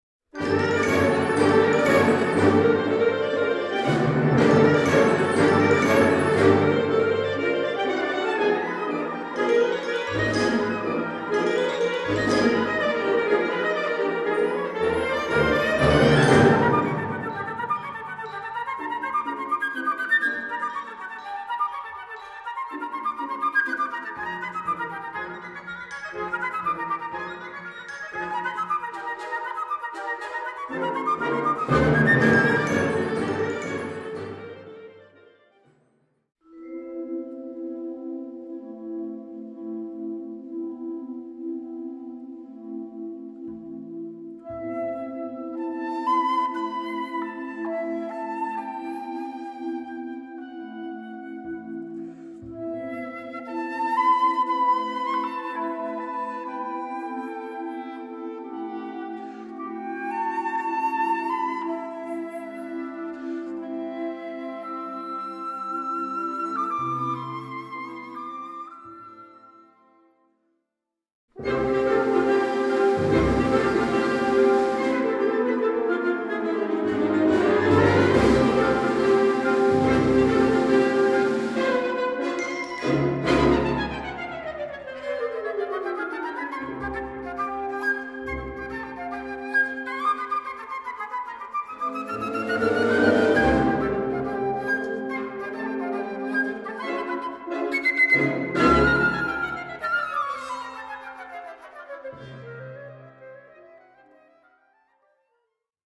Gattung: für Flöte und Blasorchester, op. 40
Besetzung: Blasorchester